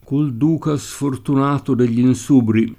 e vv&ndi©e imp#vido Sull &nSubre v#llo SoSp&nJe il kav#llo] (Zanella) — un es. poet. di pn. piana: Col duca sfortunato degl’Insubri [
kol d2ka Sfortun#to del’l’ inS2bri] (Ariosto)